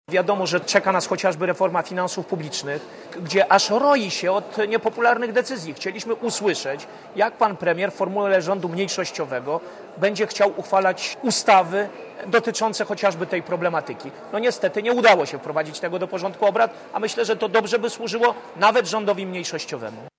Mówi Zbigniew Kuźmiuk (157Kb)